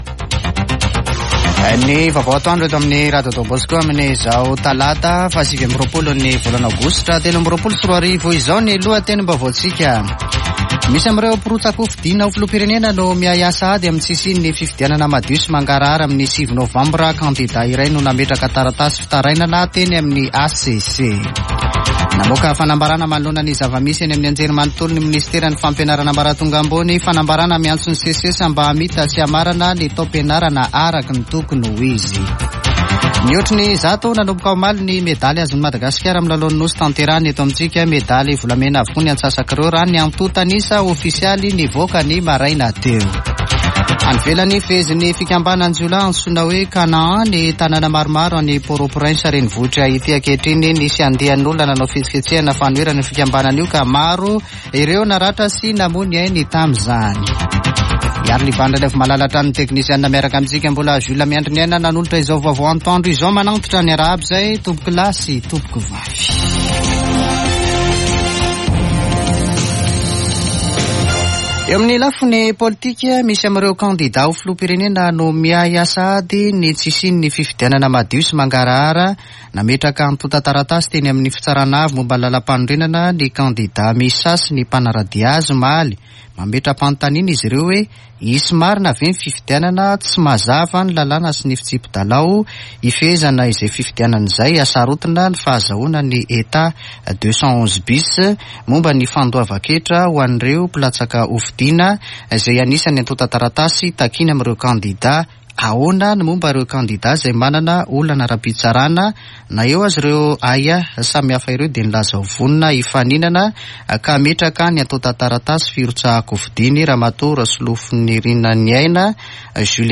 [Vaovao antoandro] Talata 29 aogositra 2023